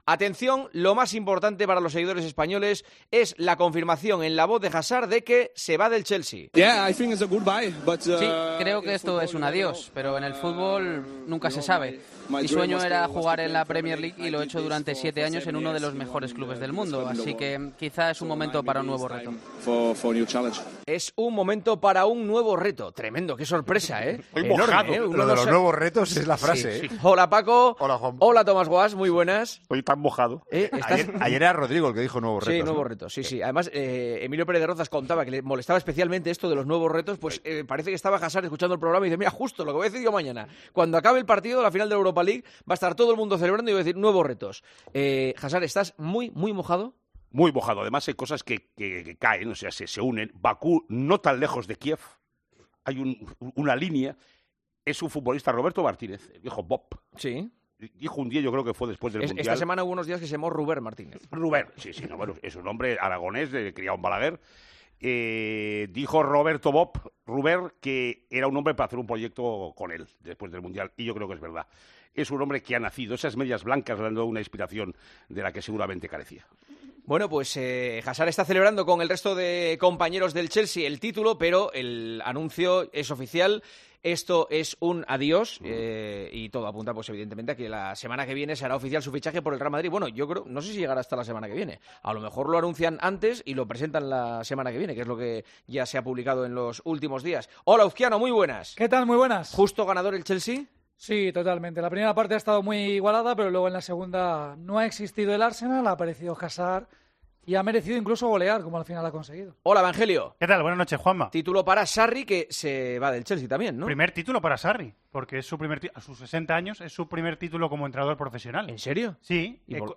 Los colaboradores de "El partidazo de COPE" valoran el adiós del belga al Chelsea y su futura labor en el Real Madrid
Todos los tertulianos valoran muy positivamente el fichaje.